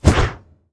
swing1.wav